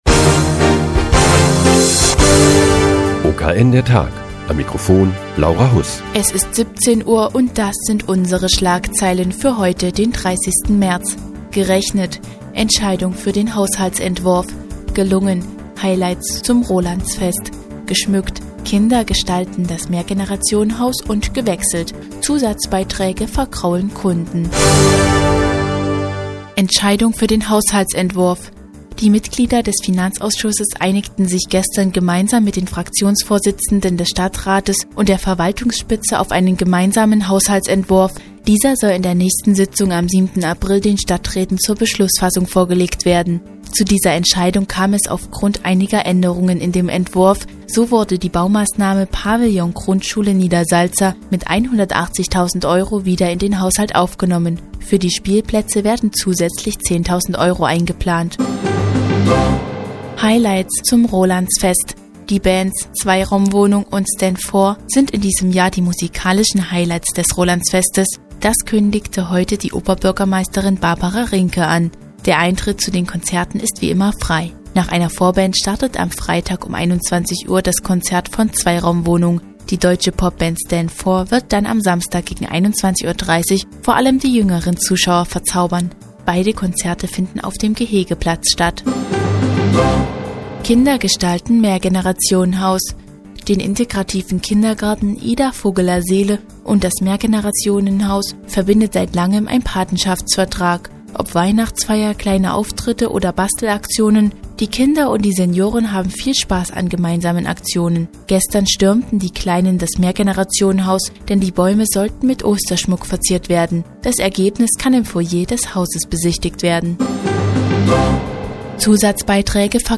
Die tägliche Nachrichtensendung des OKN ist nun auch in der nnz zu hören. Heute geht es um die Entscheidung für den Haushaltsentwurf und die Highlights zum Rolandsfest.